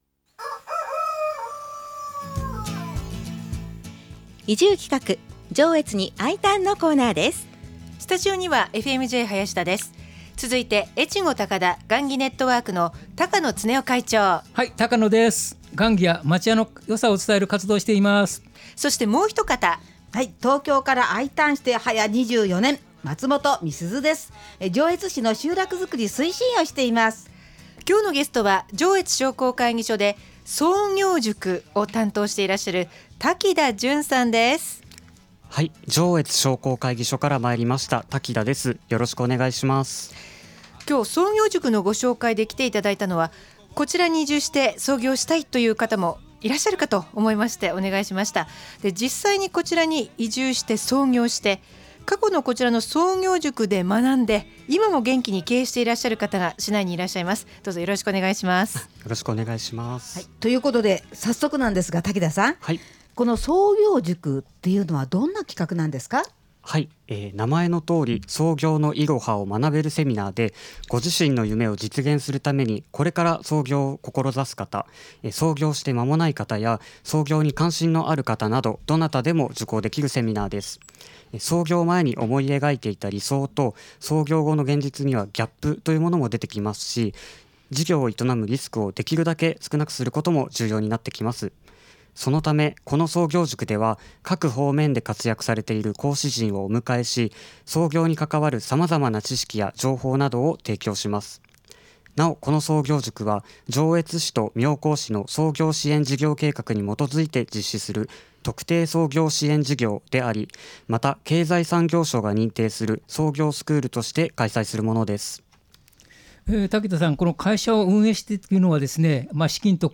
FM-Jのスタジオから移住をお誘いするコーナーです。